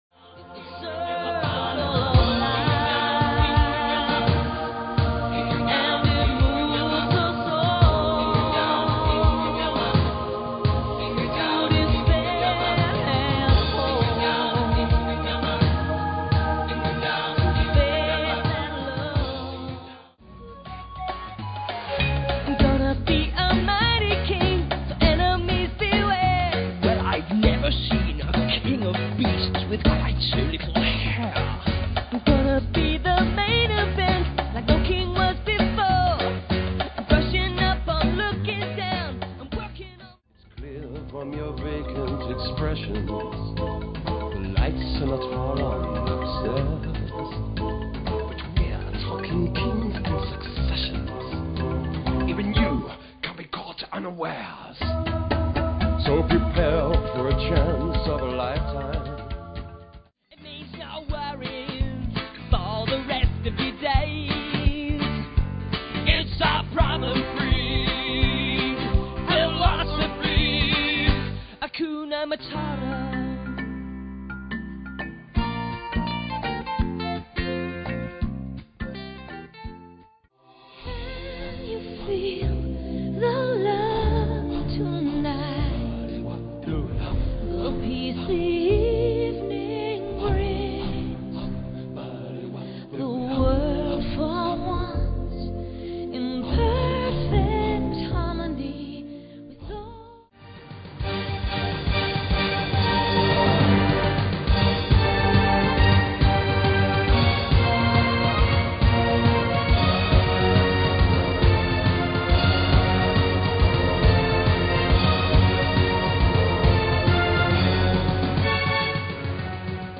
Broadway & Film